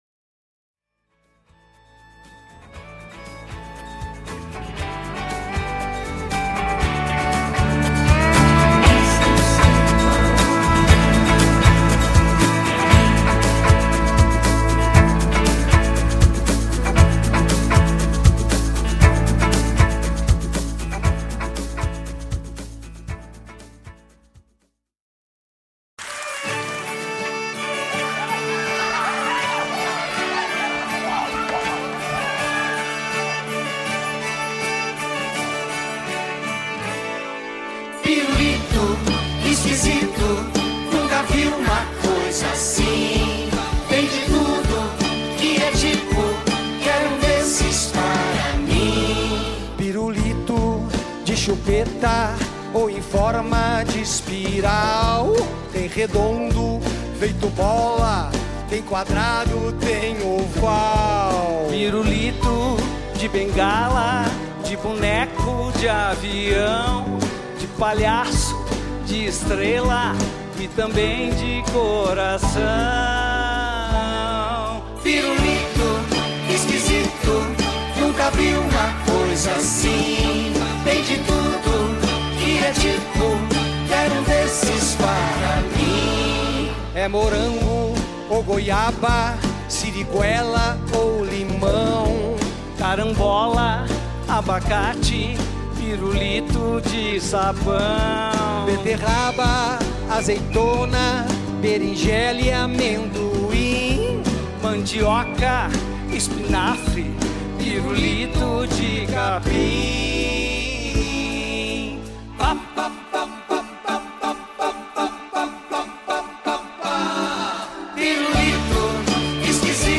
Esse registro foi feito em Porto Alegre no Teatro Bourbon Country no dia 27 de maio de 2012 e gerou o CD/DVD.